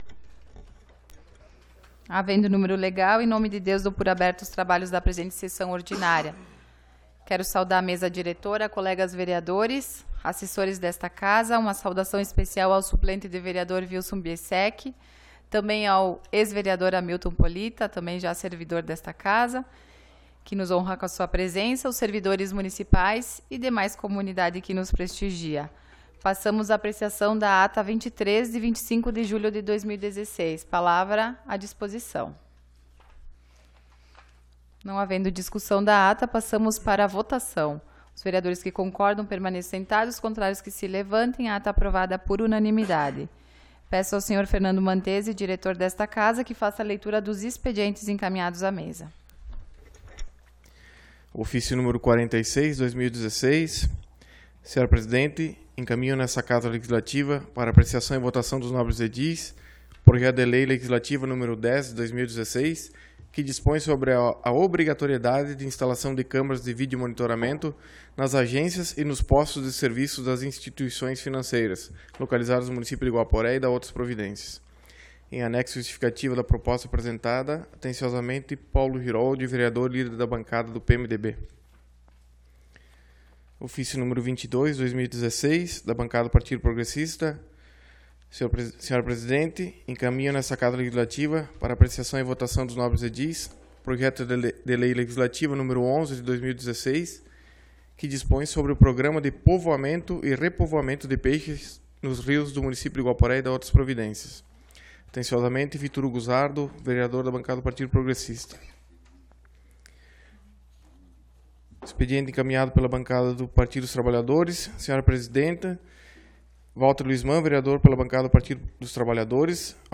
Sessão Ordinária do dia 01 de Agosto de 2016